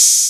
808-OpenHiHats18.wav